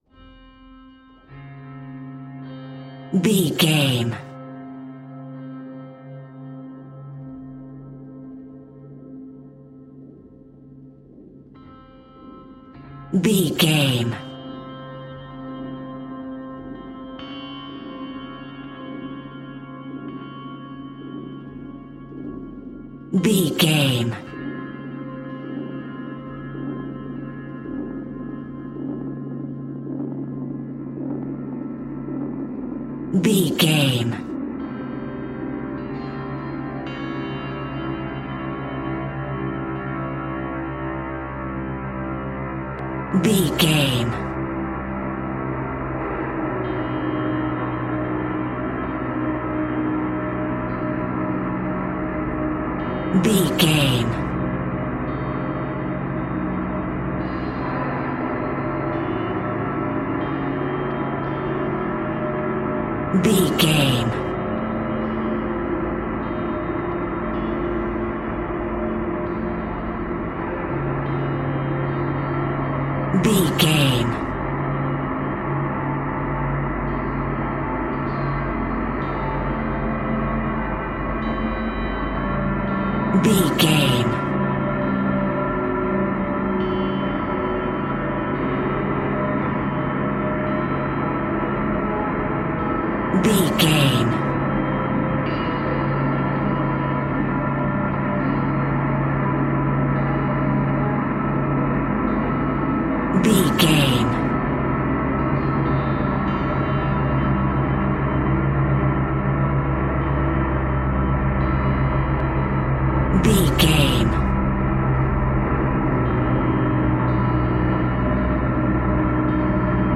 Ionian/Major
C♯
dark ambient
EBM
synths
Krautrock
instrumentals